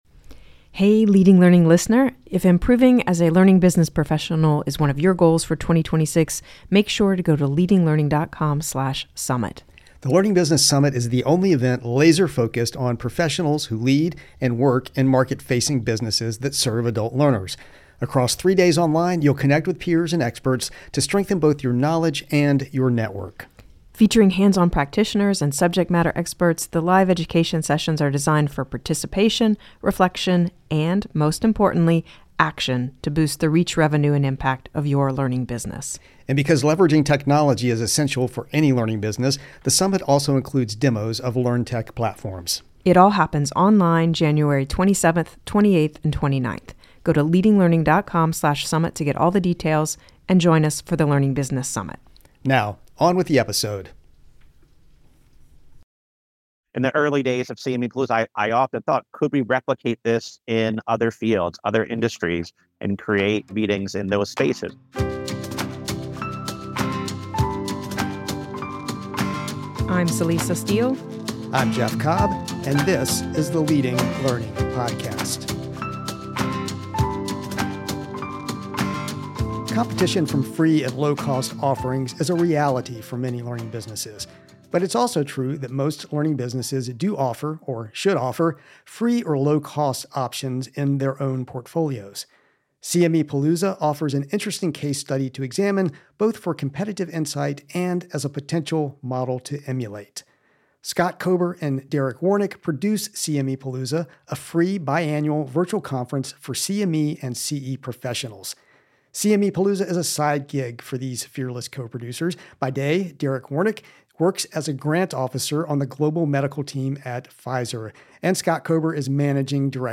talks with return guests